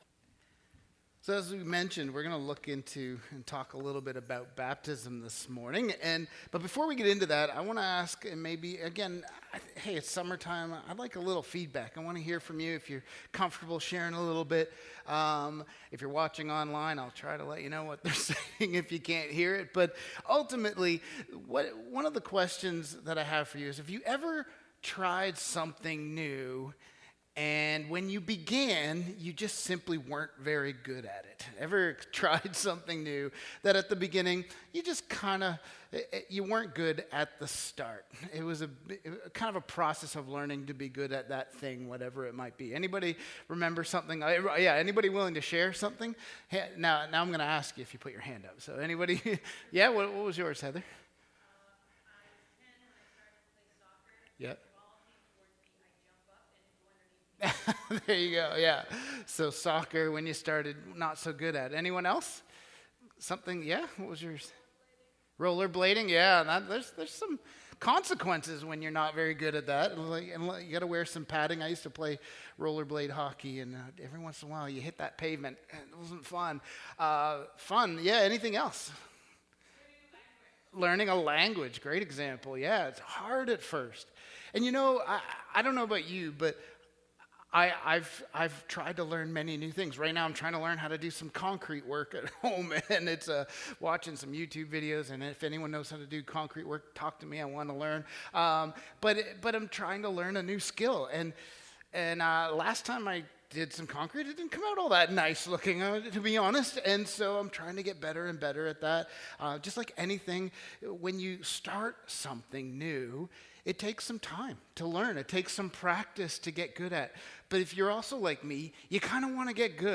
Family Service